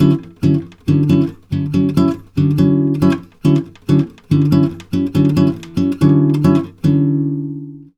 140GTR D7  3.wav